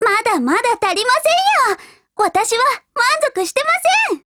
贡献 ） 协议：Copyright，其他分类： 分类:少女前线:MP5 、 分类:语音 您不可以覆盖此文件。